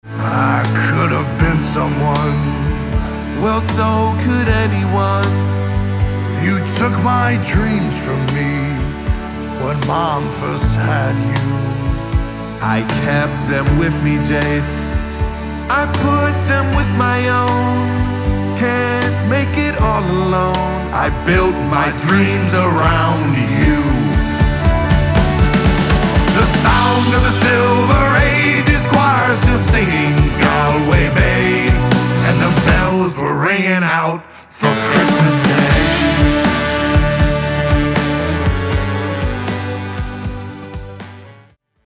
So Taylor Swift’s boyfriend also sings!